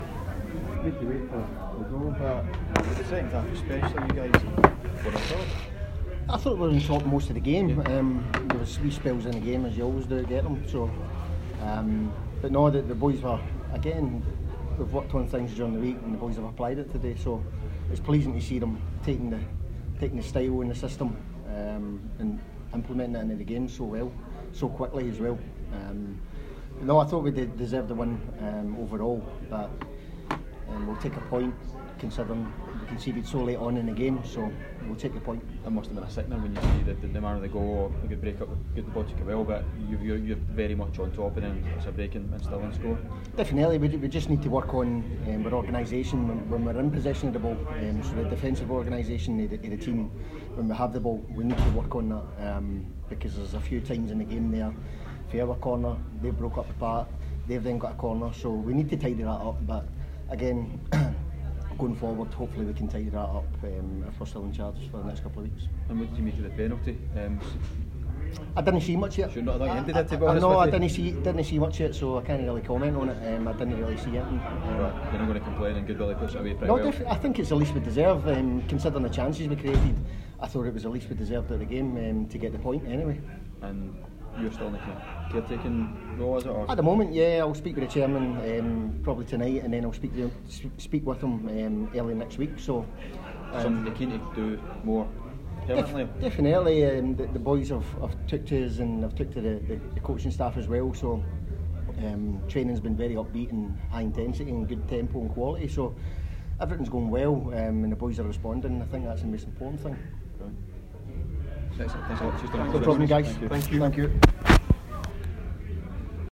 press conference after the Ladbrokes League 2 match.